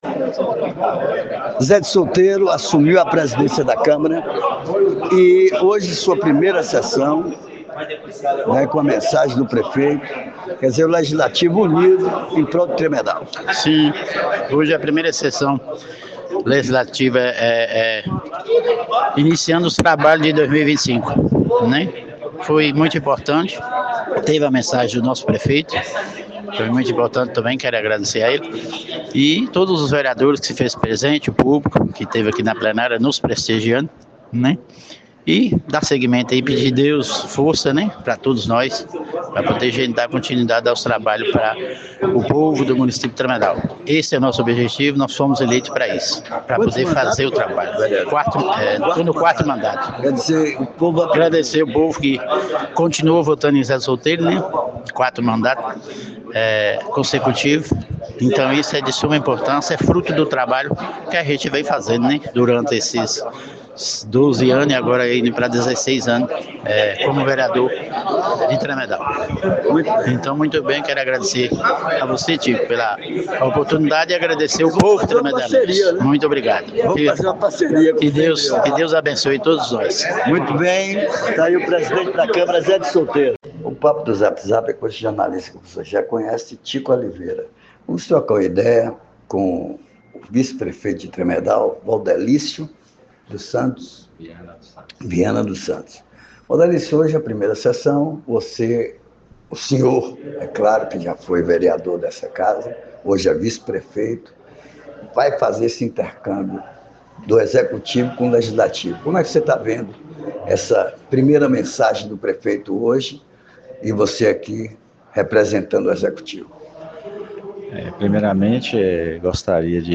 Ouça a troca de ideias entre o vice-prefeito Valdelicio, o atual presidente da câmara, Zé de Solteiro, o ex-presidente da câmara, Erasmo, e o vereador mais votado no último pleito, Tel de Lagoa Preta.
A troca de ideia impactante ocorreu no podcast
O ex-vereador Daniel leu a mensagem do prefeito Dr. Zé Bahia, que se comprometeu com a infraestrutura na cidade e no município. Ele garantiu que continuará lutando pelo abastecimento de água no distrito de Lagoa Preta, e prometeu muito mais estrutura no sistema educacional e uma saúde cada vez melhor para a população.